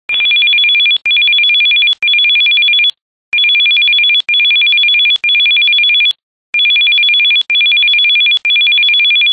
Telephone ring 2.wma